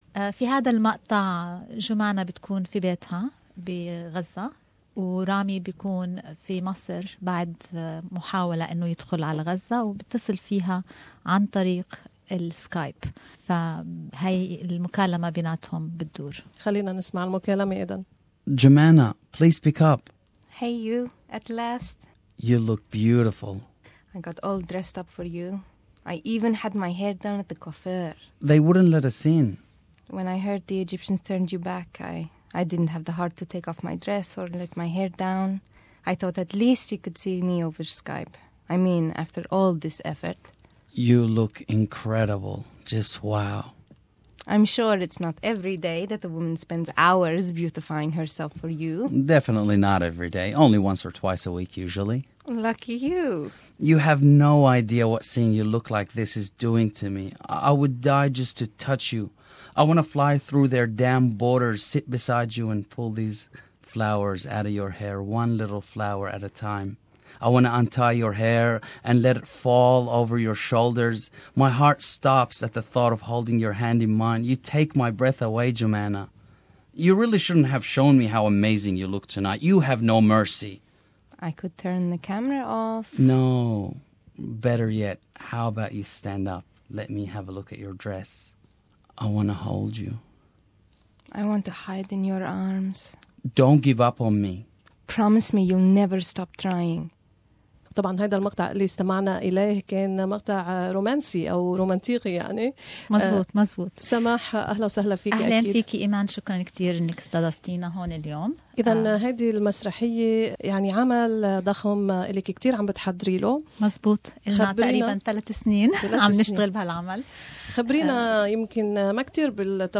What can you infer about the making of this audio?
We interviewed her with two of the cast members in SBS's studios in Melbourne.